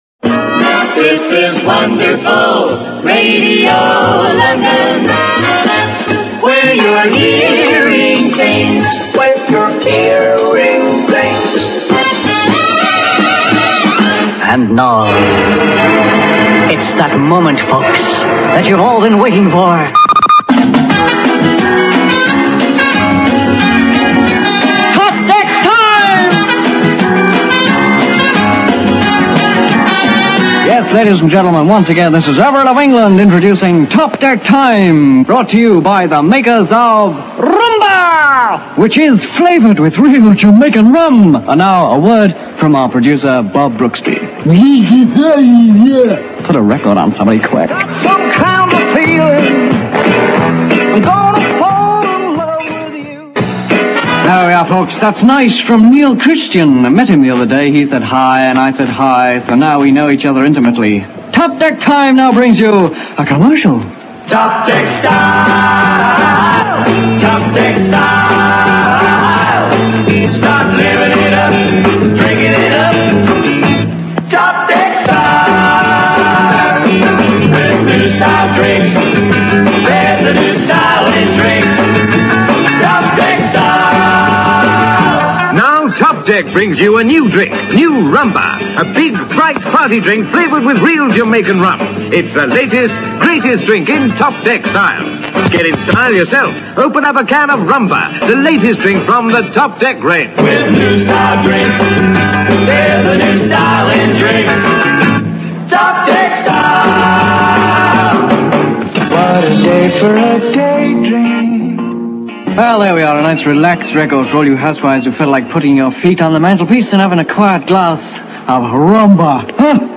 click to hear audio Kenny Everett introducing Top Deck Time in May 1966 (duration 4 minutes 40 seconds)